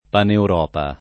[ paneur 0 pa ]